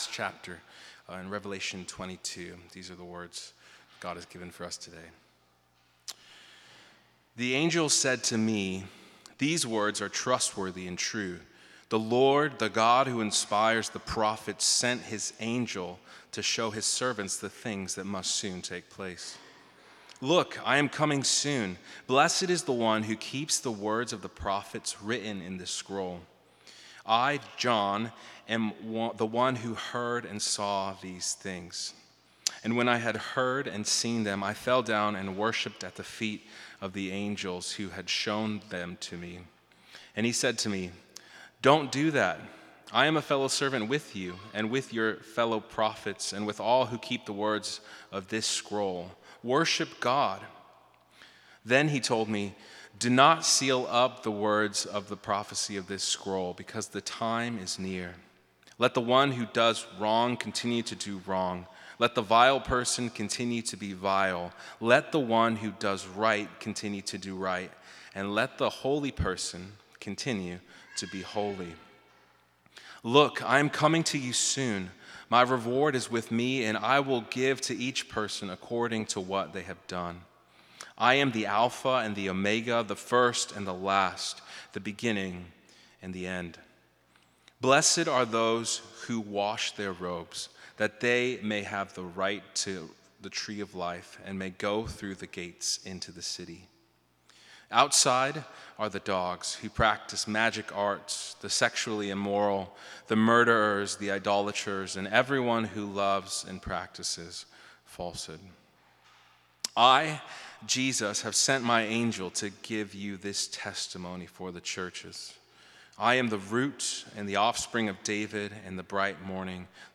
This sermon concludes a series on 3 Strange Prayers, focusing on the final prayer in the Bible, “Come, Lord Jesus.”